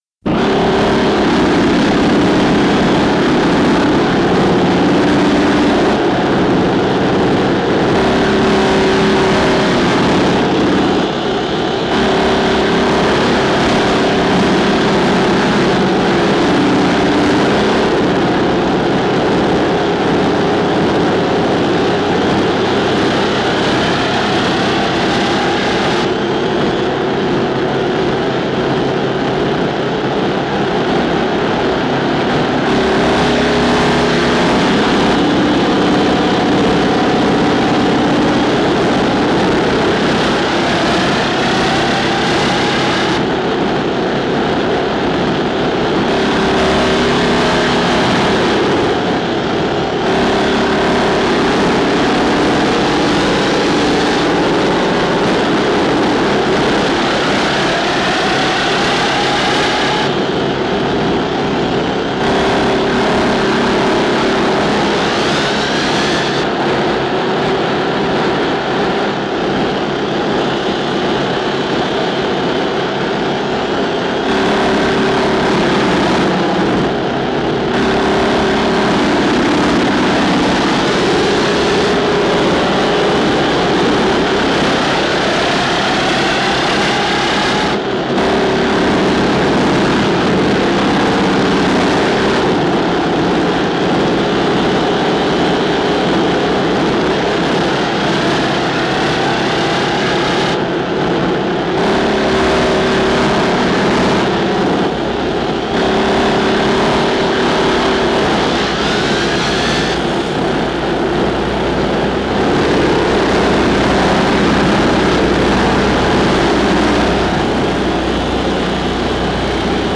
“National Grid” is a work of electromagnetic sound art, originally created using VLF-band radio technology, later using direct line noise from live mains electricity.
This presentation discusses technical, musical and symbolic aspects of “National Grid,” in relation to art history, and to experimental and classical music - featuring “Dnieprostrot” (Dnieper Hydro-Electric Power Station) by the Ukrainian composer Yuliy Sergeievitch Meitus, also in relation to literature - featuring short readings from the writers H.G. Wells, William F. Temple and Bruno Schulz. Documentation + audio commentary